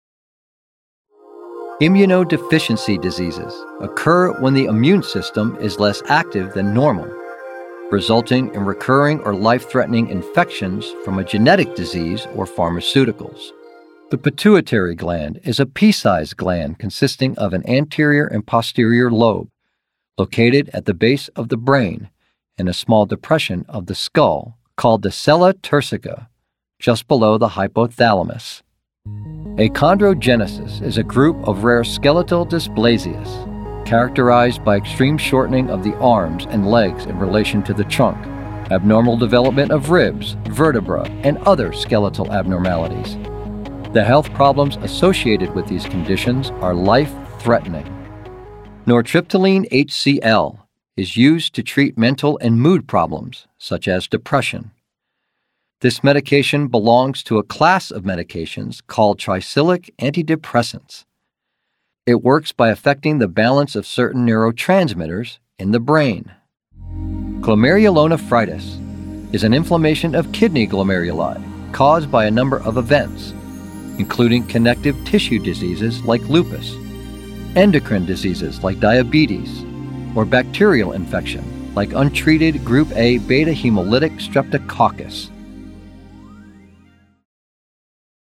Medical
English - Midwestern U.S. English
Middle Aged
Senior